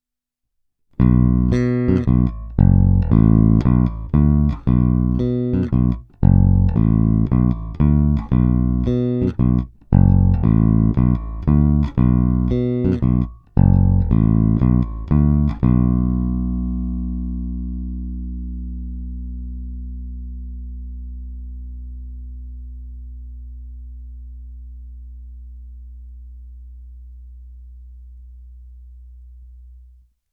Zvukově je to naprosto klasický a opravdu skvělý Jazz Bass.
Není-li uvedeno jinak, následující nahrávky jsou provedeny rovnou do zvukové karty a bez stažené tónové clony.
Struny jsou roundwound niklové Fender 45-100 v dobrém stavu.
Oba snímače